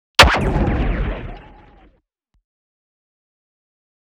SoundEffects / Bullet / 默认武器.wav
默认武器.wav